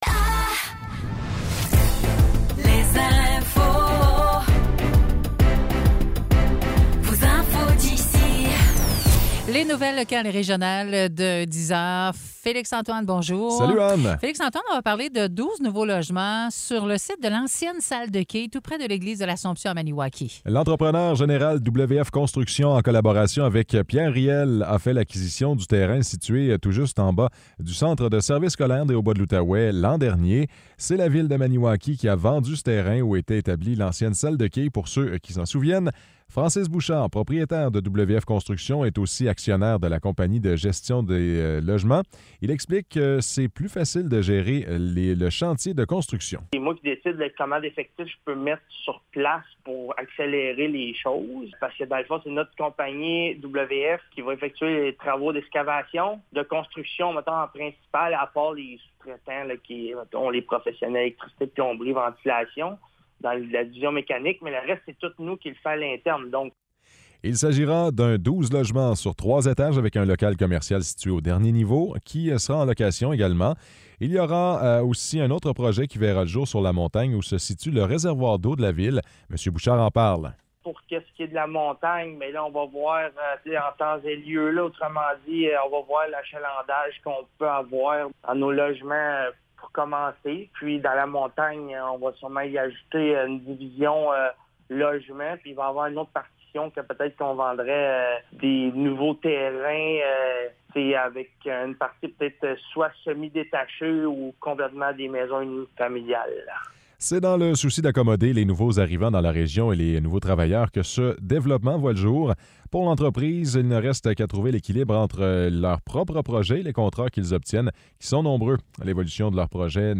Nouvelles locales - 21 novembre 2023 - 10 h